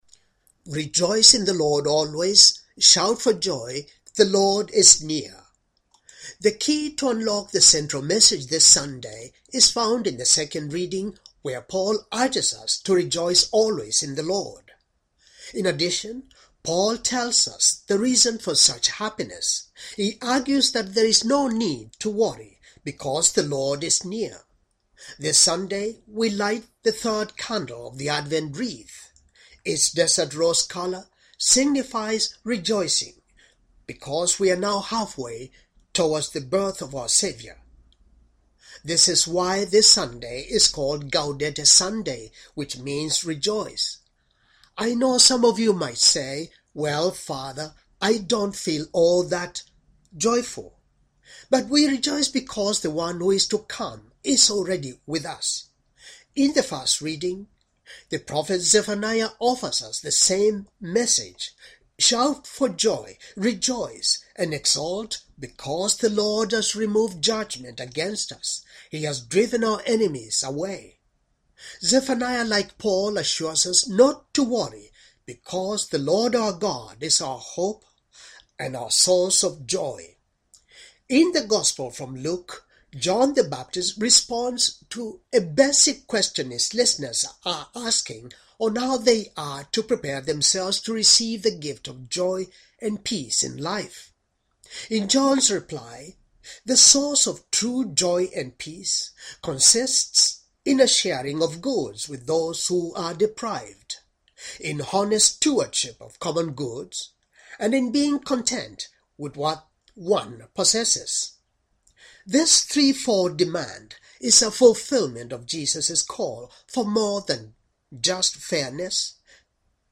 Homily for third Sunday of Advent, year c